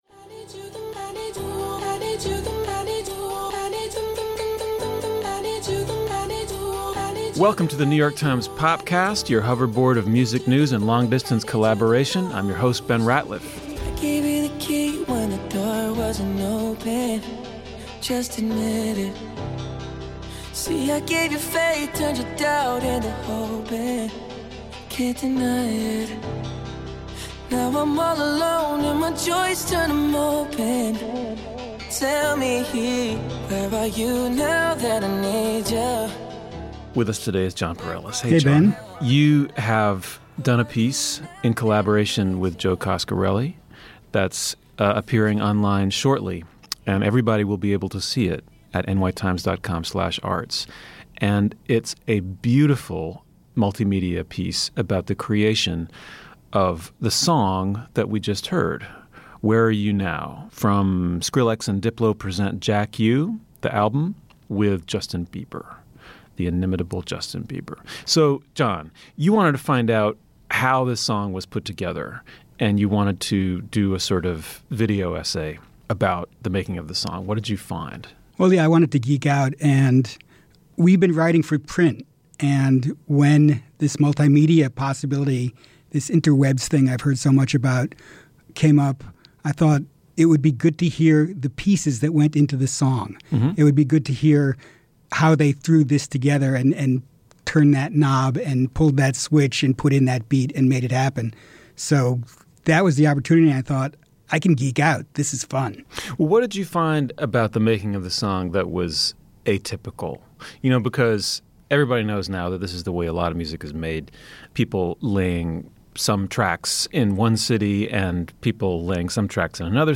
Times music critics discuss Justin Bieber’s collaboration with Skrillex and Diplo.